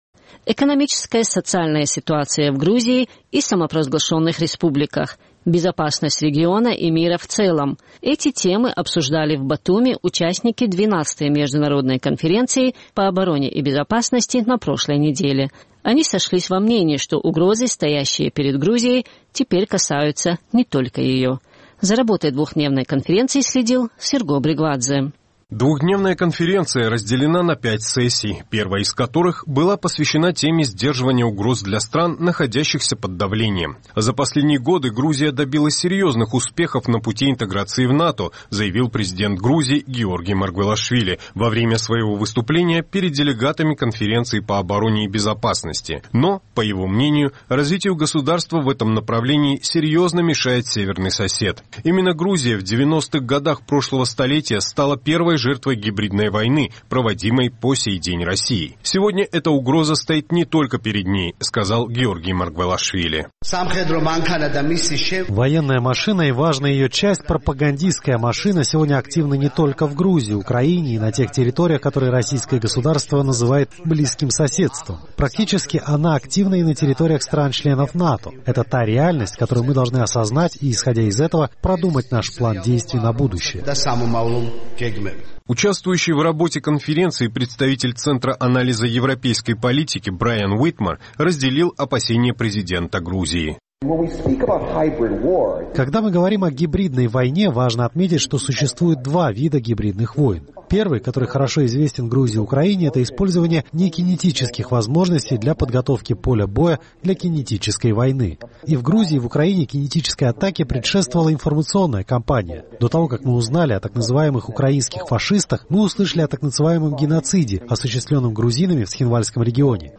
Тяжелая ситуация в сфере безопасности, вызванная милитаризацией, бесконтрольным ввозом оружия и участившимися российскими военными учениями на территории государства, сегодня угрожает всему региону, заявил во время своего выступления премьер-министр Грузии Мамука Бахтадзе: